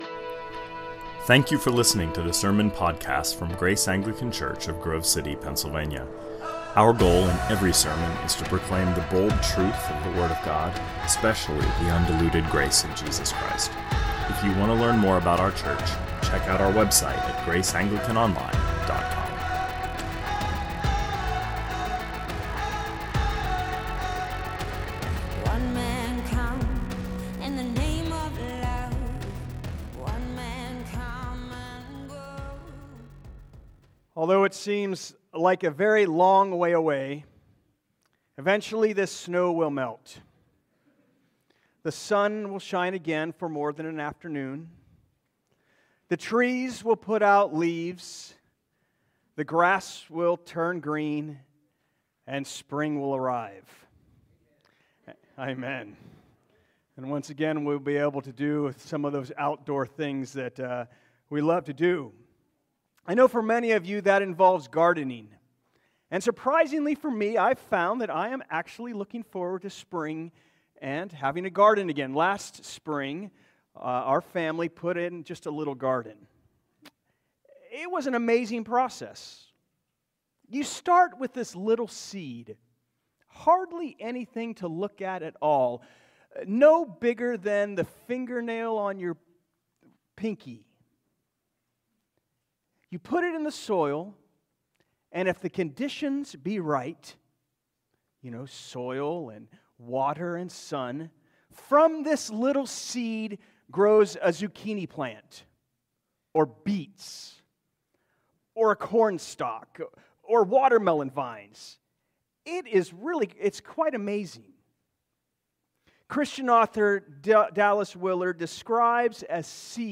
2025 Sermons